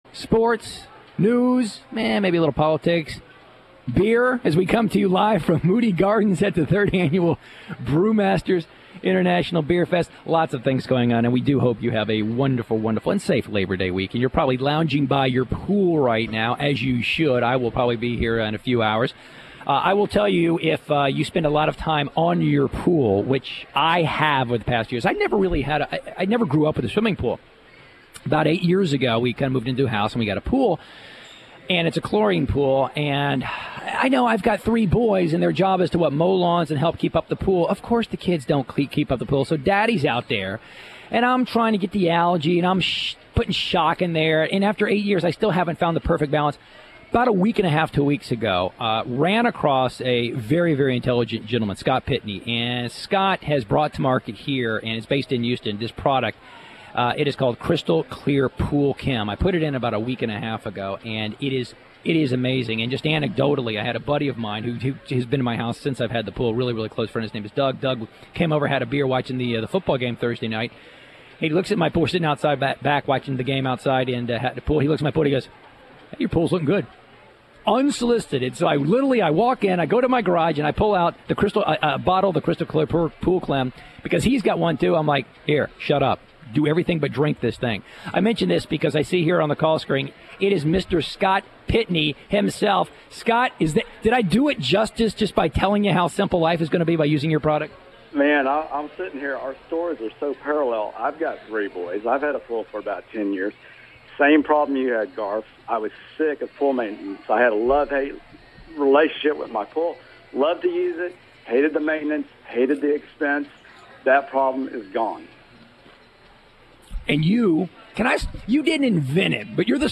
Crystal-Clear-Interview-on-KPRC-9.1.12-Copy.mp3